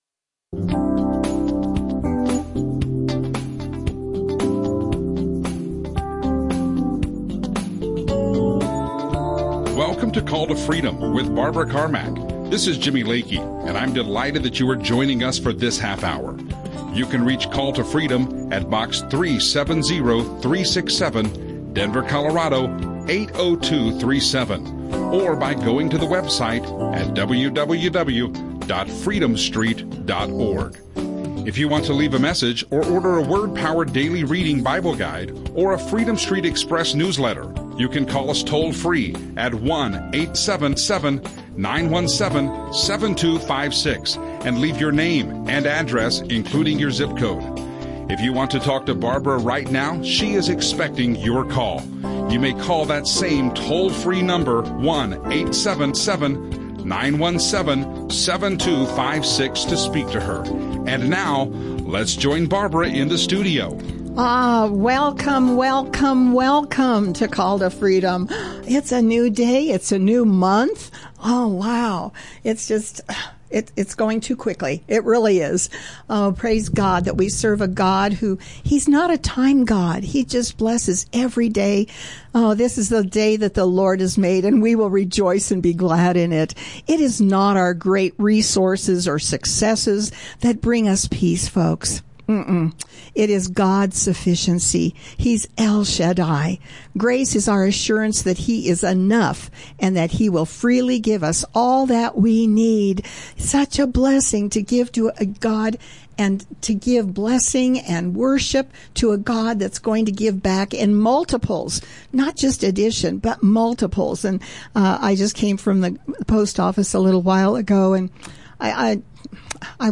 Audio teachings
Christian radio